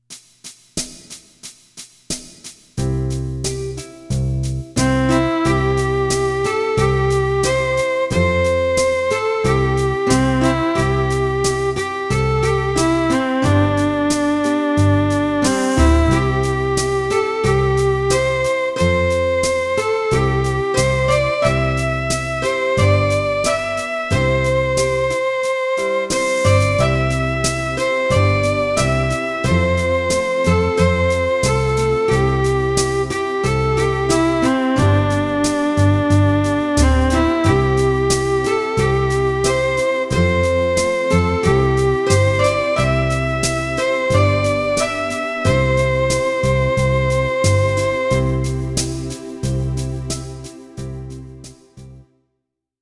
Flauta Música: MIDI 1.